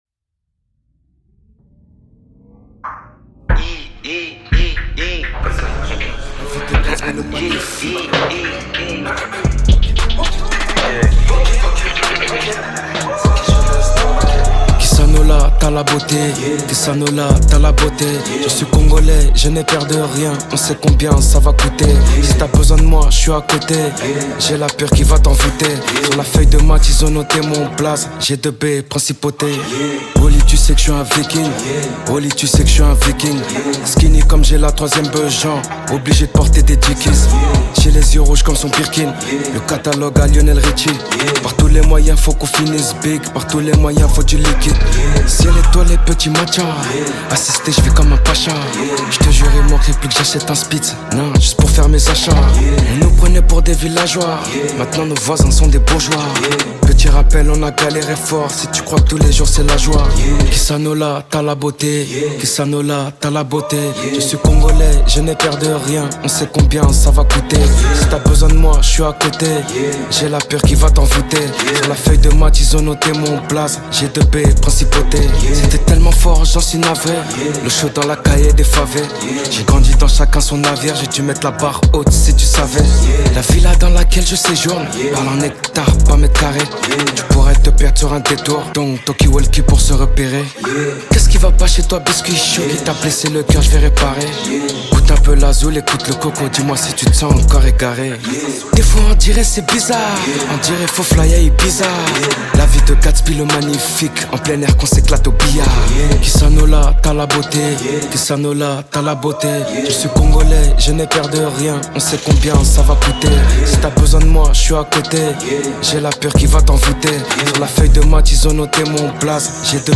41/100 Genres : french rap, pop urbaine Télécharger